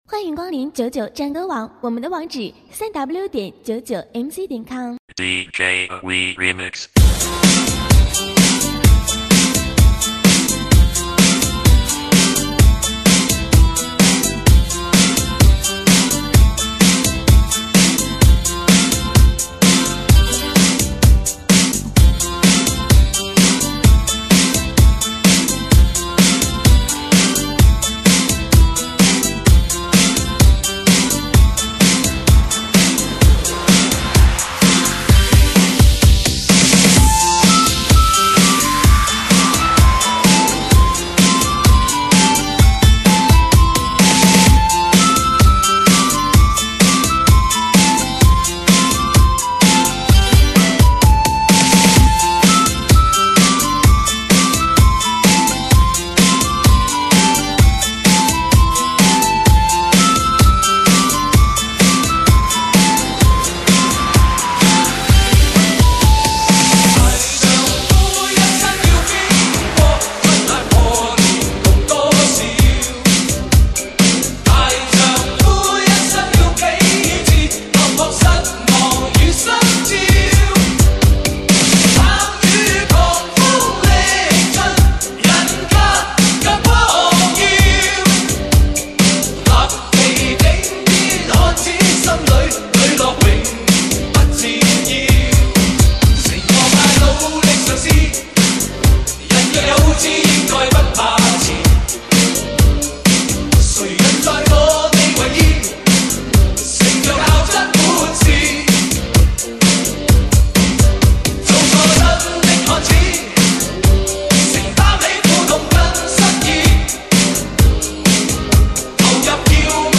Mc喊麦